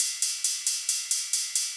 K-7 Ride.wav